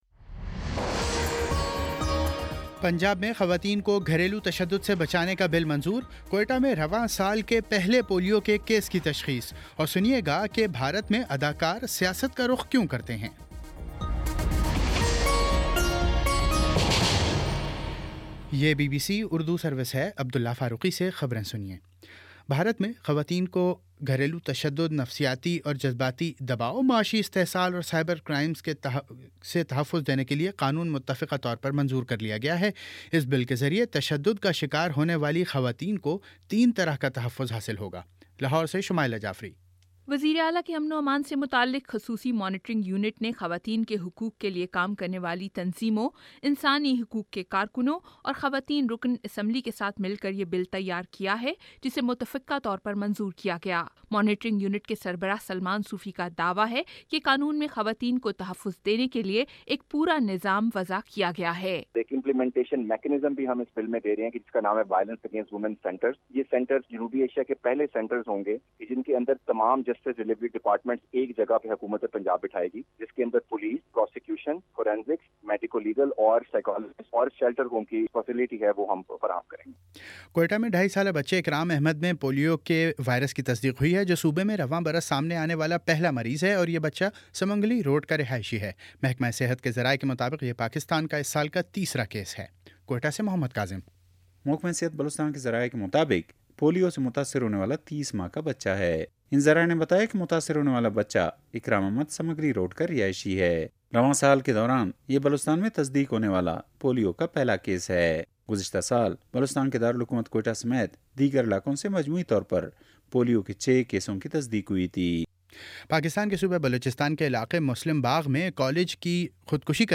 فروری 24 : شام چھ بجے کا نیوز بُلیٹن